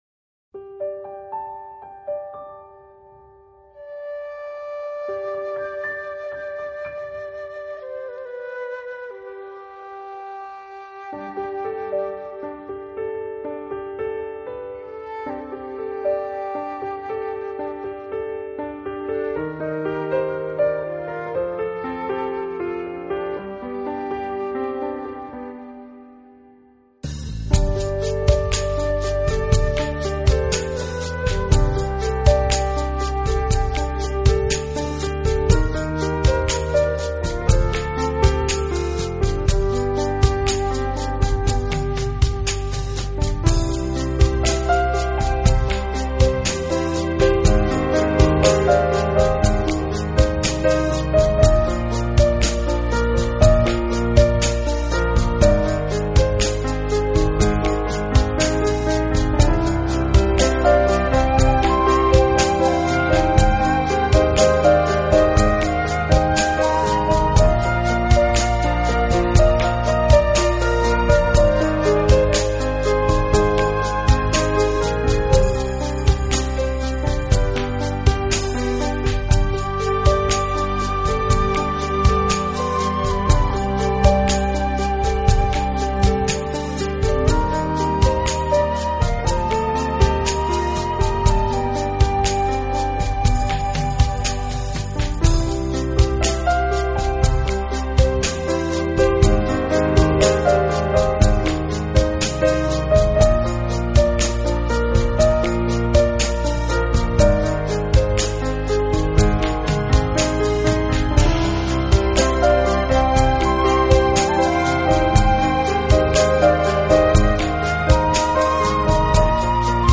这辑钢琴旋律非常优美。